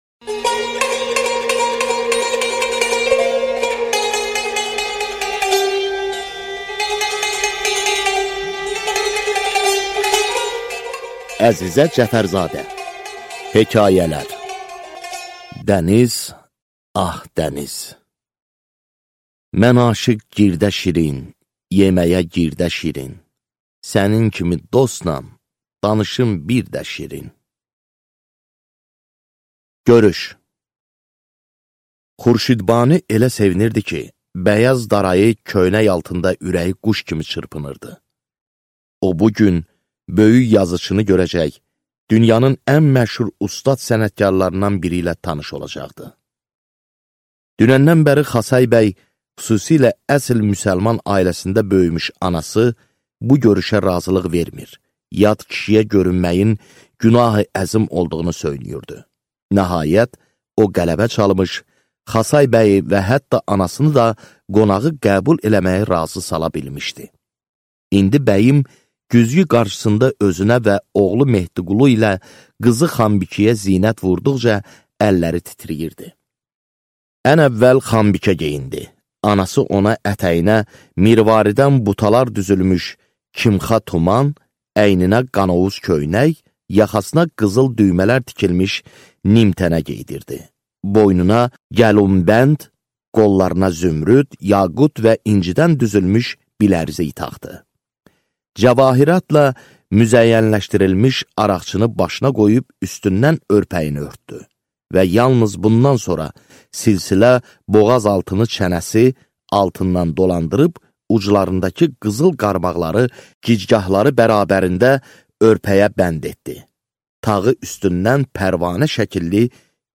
Аудиокнига Natəvan haqqında hekayələr | Библиотека аудиокниг